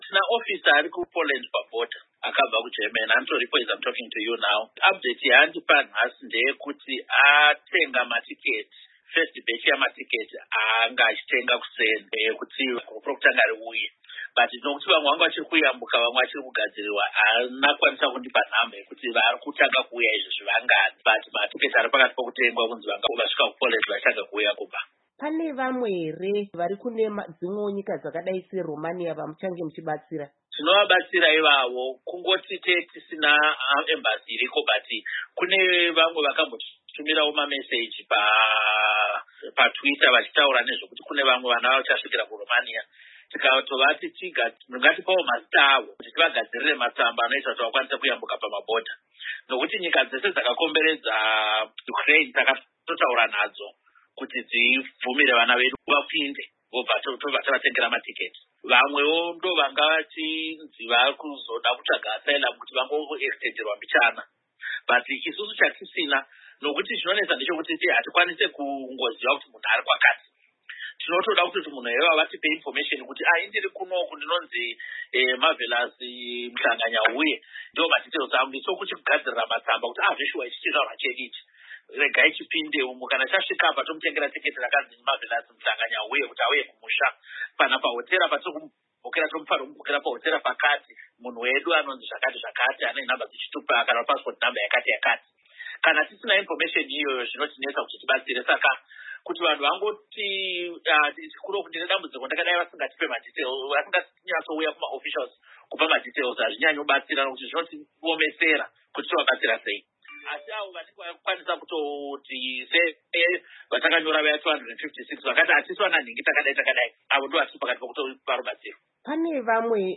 Hurukuro na VaNick Mangwana